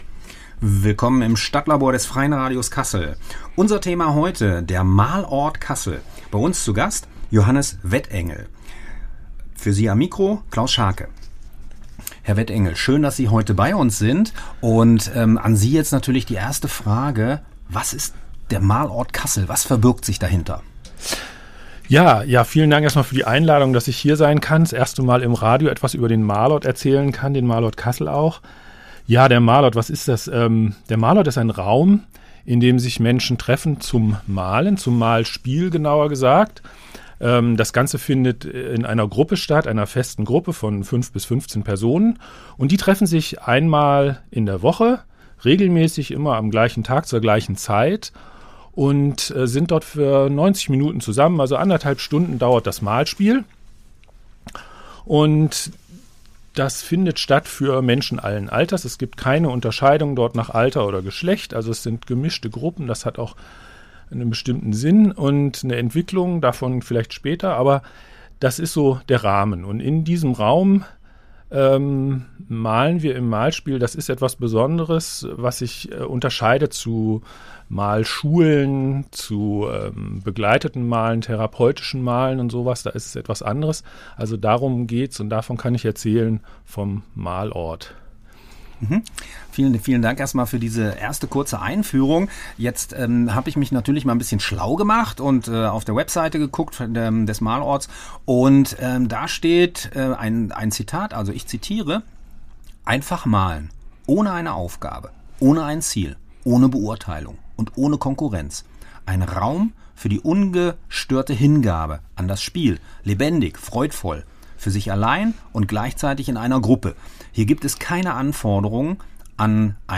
StadtLabor im Freien Radio Kassel: Der Malort Kassel ~ StadtGespräch